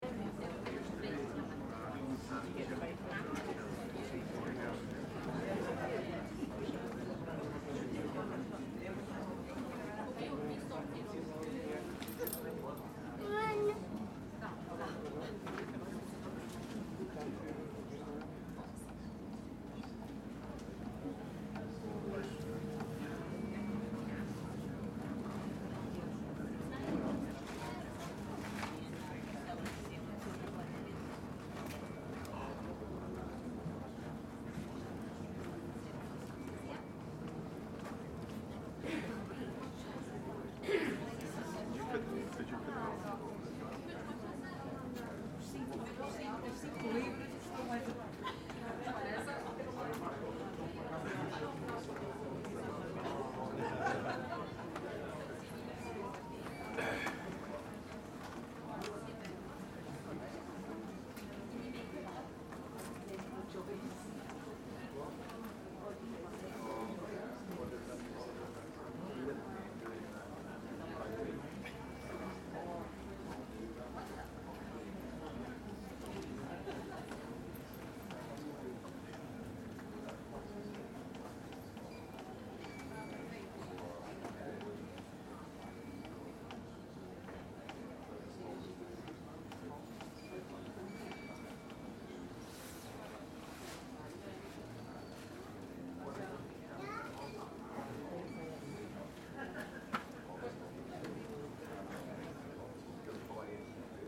Crowds, Walla, People Drift Away, Quiet Voices, Scottish & English, Airport, Glasgow SND133495.mp3